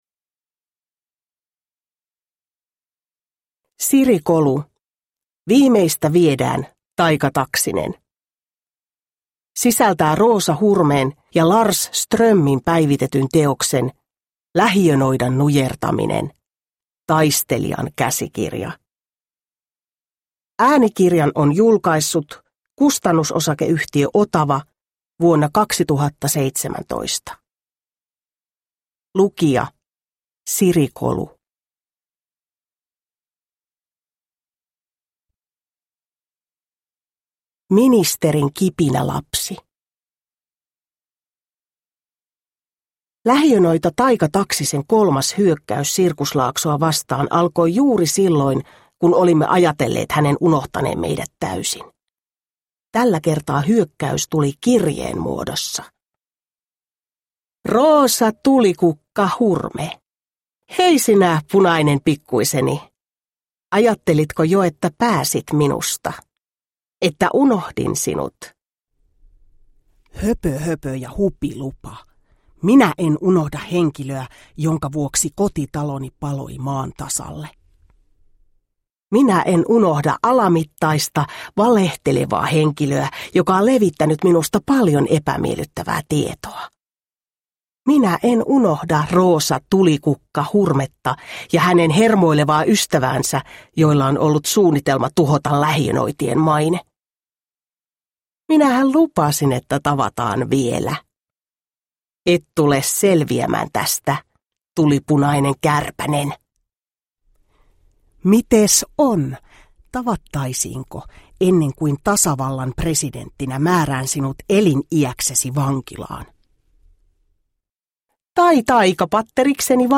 Viimeistä viedään, Taika Taksinen – Ljudbok – Laddas ner
Uppläsare: Siri Kolu